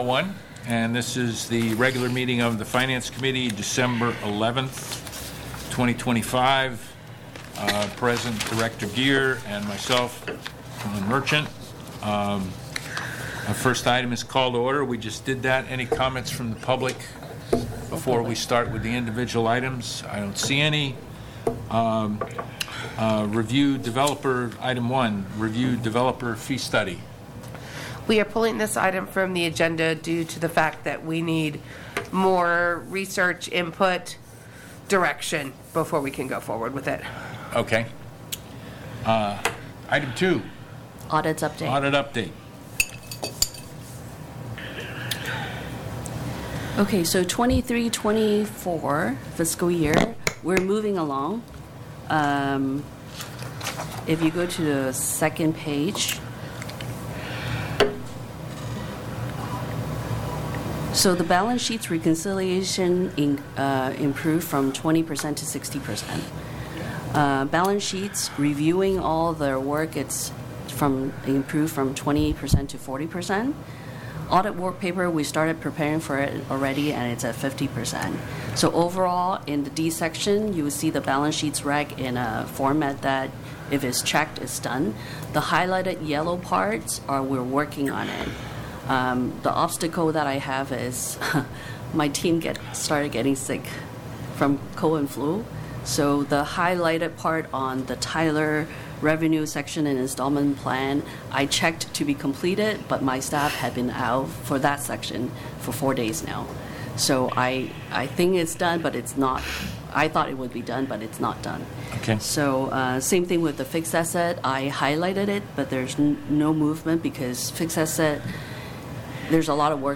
Finance Committee Meeting